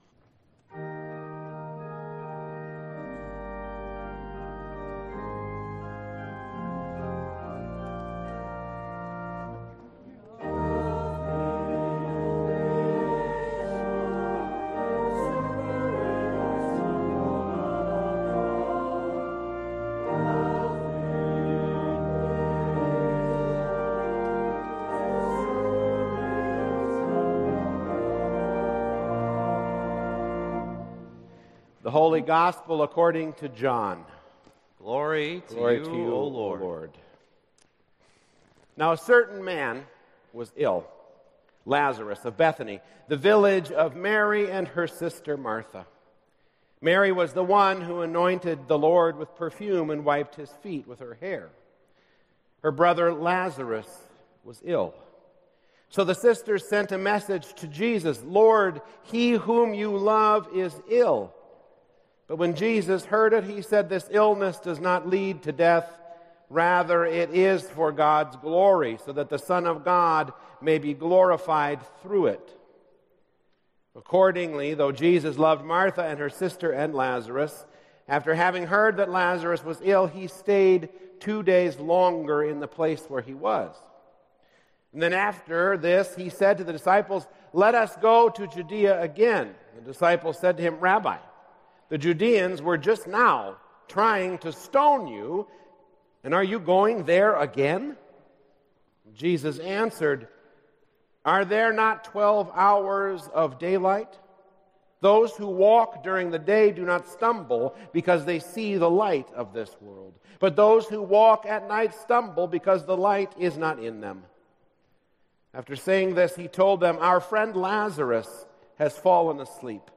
Worship Services | Christ The King Lutheran Church